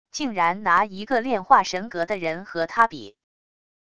竟然拿一个炼化神格的人和他比wav音频生成系统WAV Audio Player